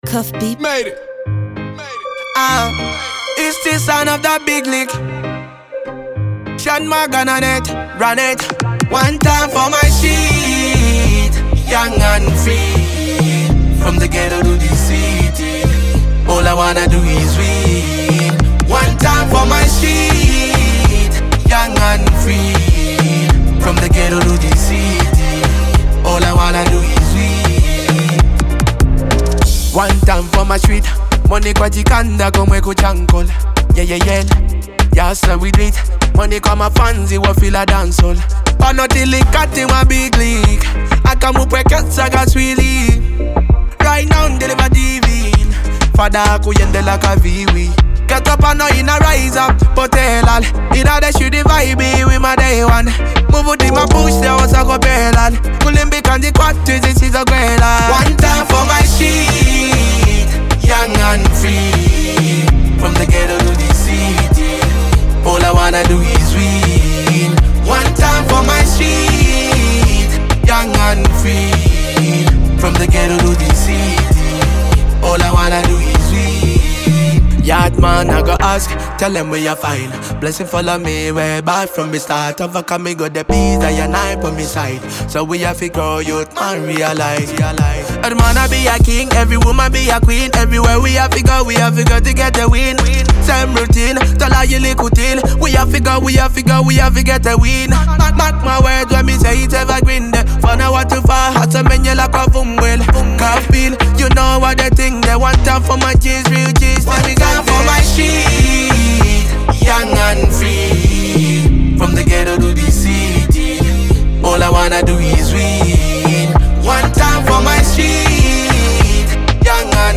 Genre : Afro Dancehall